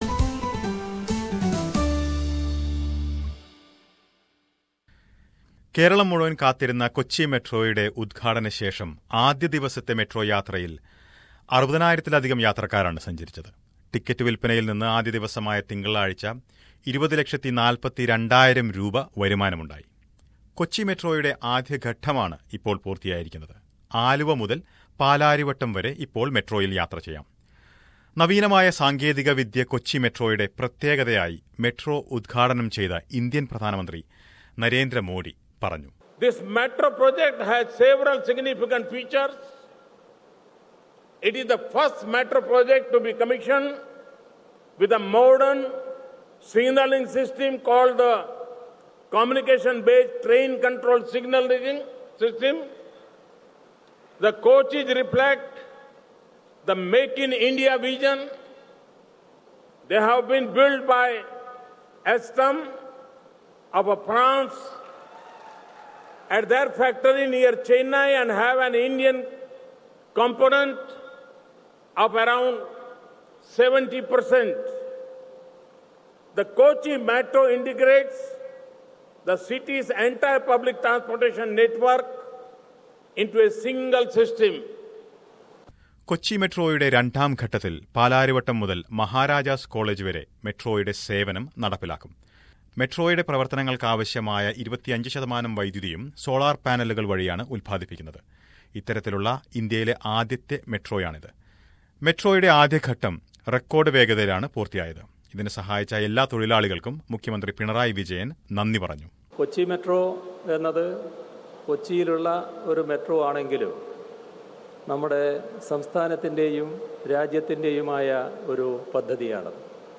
എന്നാൽ മെട്രോ സർവീസ് തുടങ്ങിയതോടെ യാത്ര ചെയ്യുവാനായി ആയിരകണക്കിന് ആളുകളാണ് എത്തുന്നത്. കൊച്ചി മെട്രോയിൽ ആദ്യ ദിനങ്ങളിൽ യാത്രചെയ്തവരുടെ അനുഭവങ്ങൾ ഉൾപ്പെടുത്തിയ റിപ്പോർട്ട് കേൾക്കാം മുകളിലെ പ്ലെയറിൽ നിന്ന്.